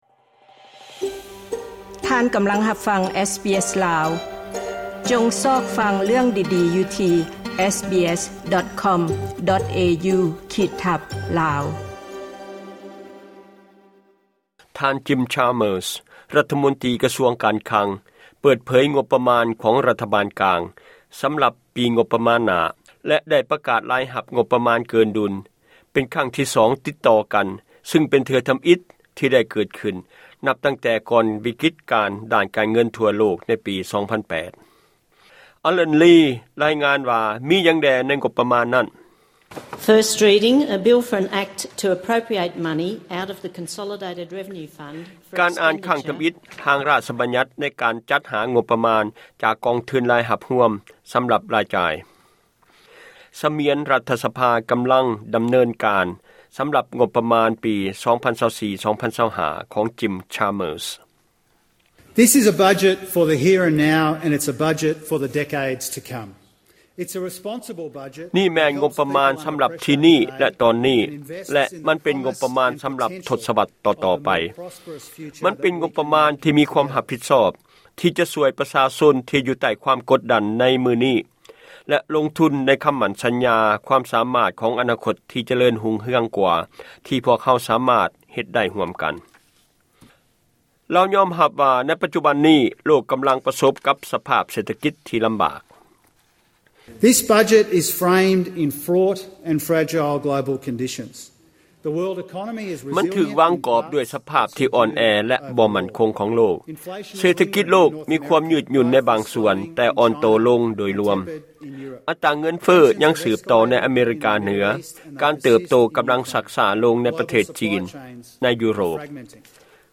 ລາຍງານ ງົບປະມານ 2024.